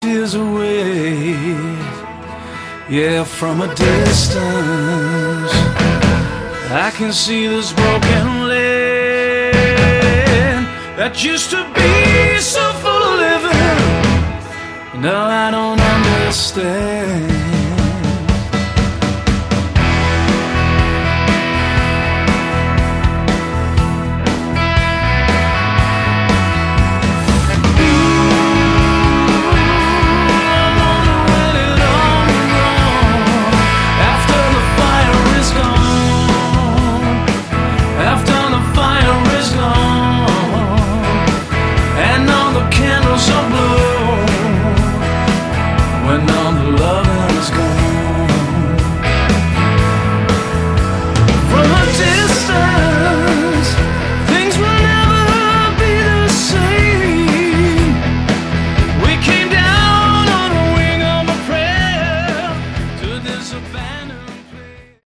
Category: Hard Rock
vocals, guitar, keyboards
bass
drums